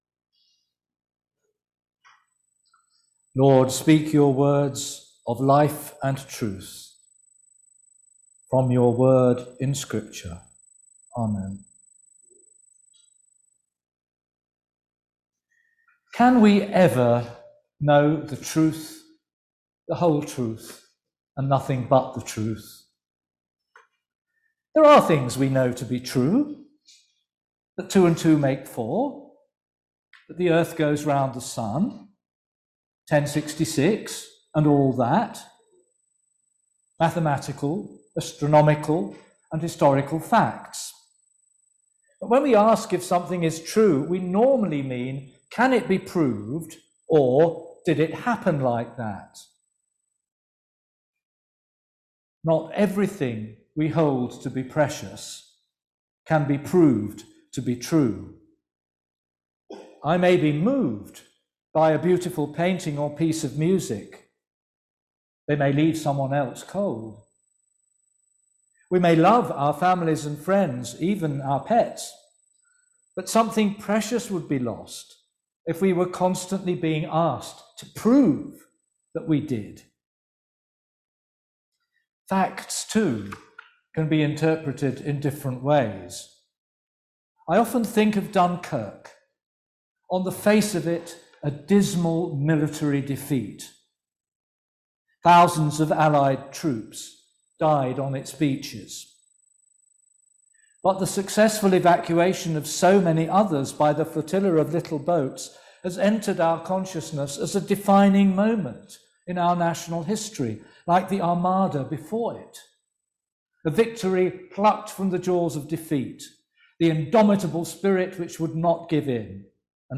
Service: Blended worship